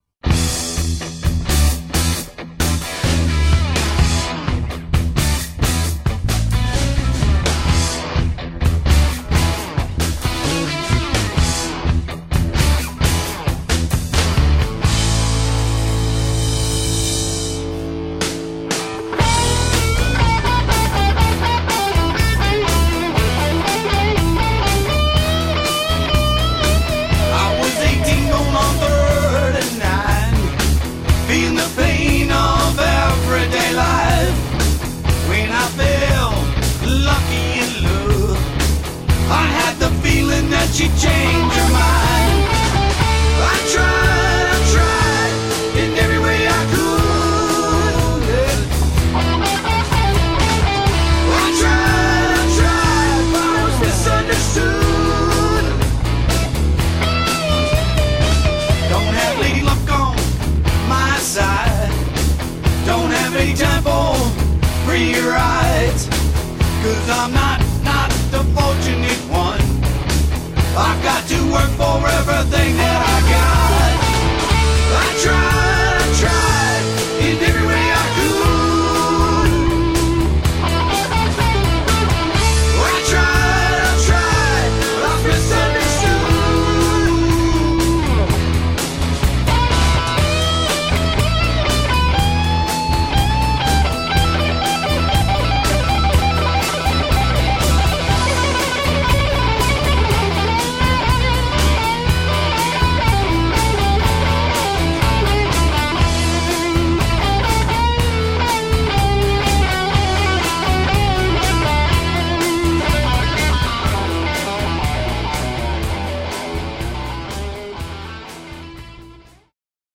A Redwood City, Calif. Rock Band,
impressive array of tunes in the classic rock genré.